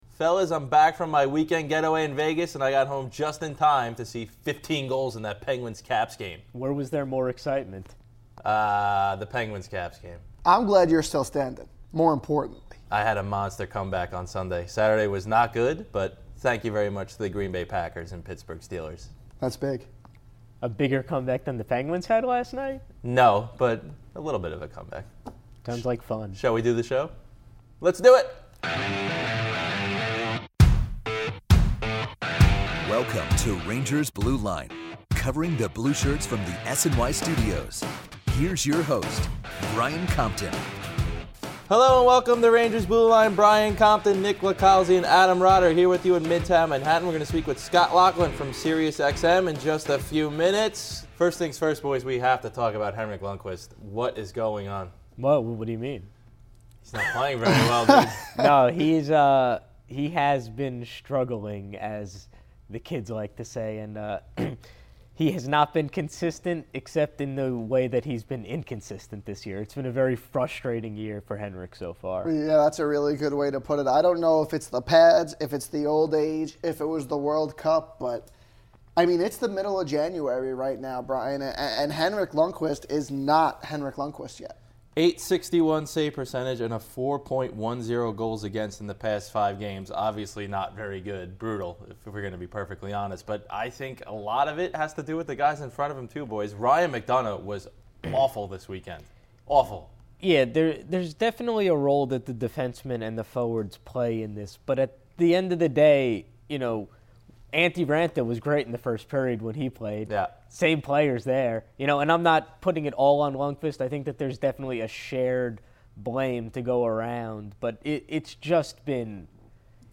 calls in to the show for his two cents on Lundqvist, and to talk about the dominant Metropolitan division.